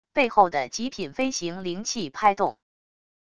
背后的极品飞行灵器拍动wav音频